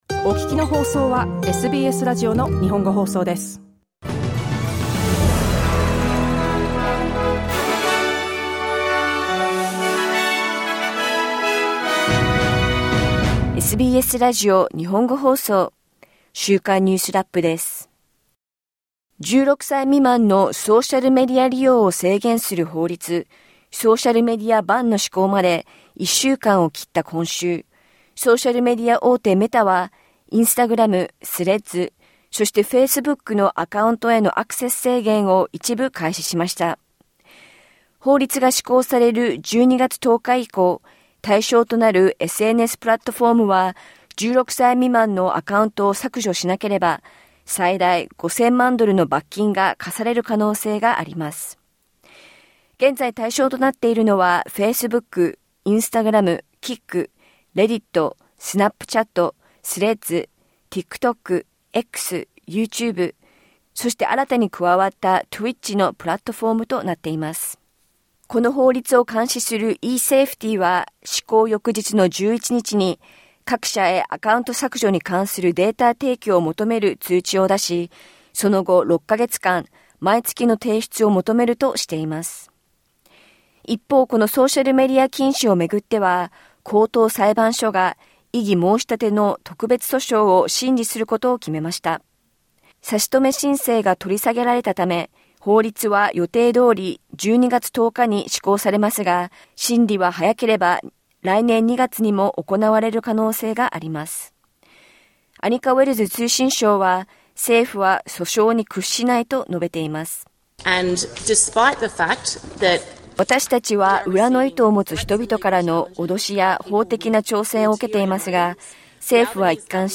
欧州最大級の国別対抗ソングコンテスト「ユーロビジョン」の来年の大会をめぐり、ヨーロッパ放送連合（EBU）がイスラエルの参加を認めました。1週間を振り返るニュースラップです。